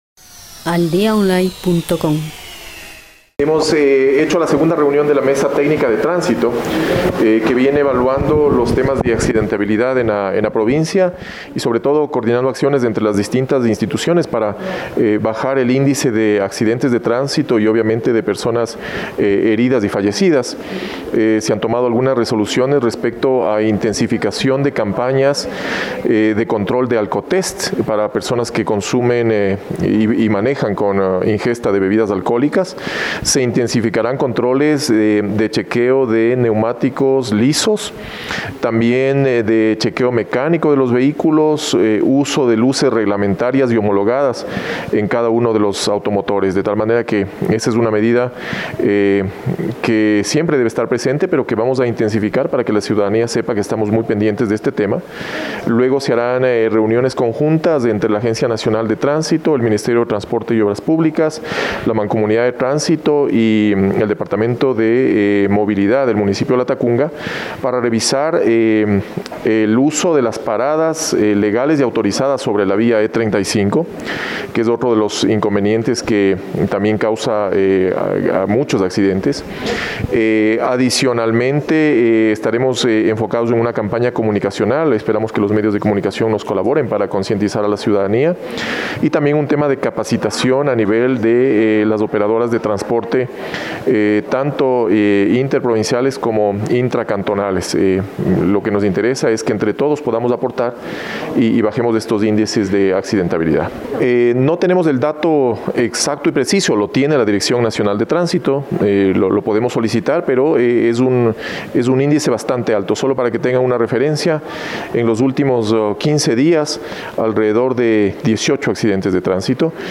Interviene: Oswaldo Coronel, Gobernador de Cotopaxi.